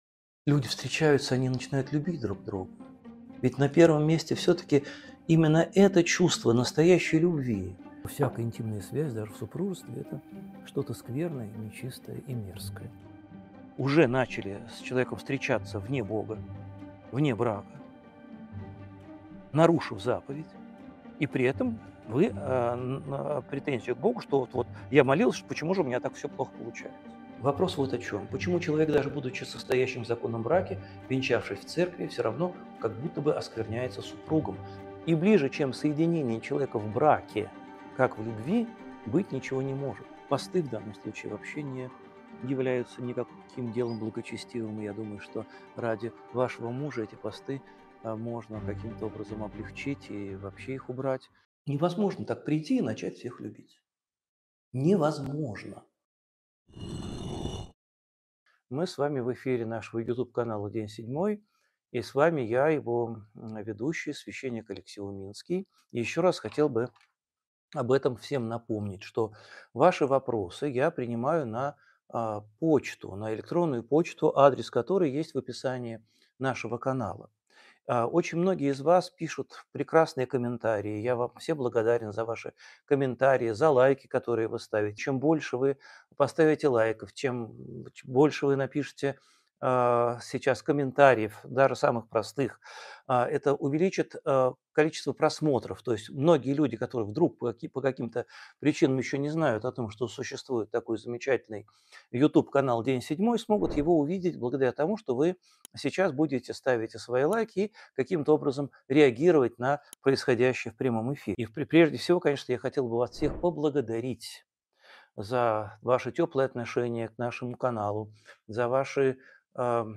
Эфир ведёт Алексей Уминский